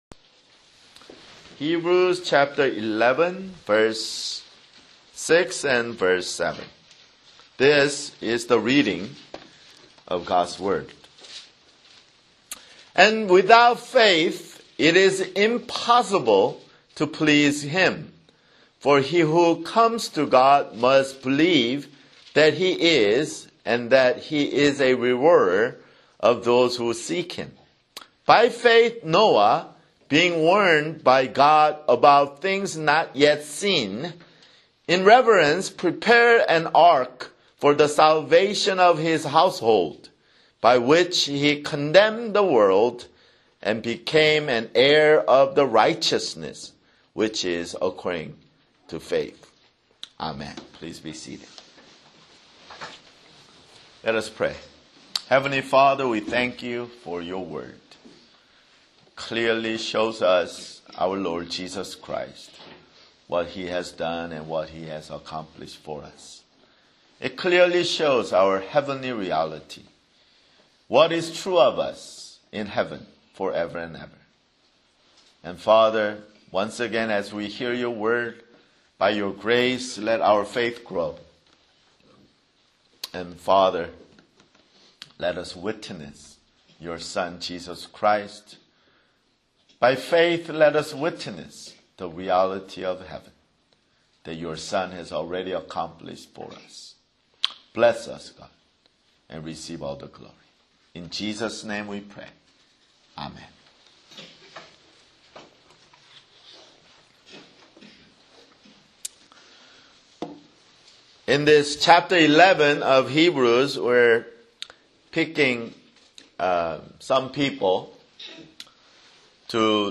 [Sermon] Hebrews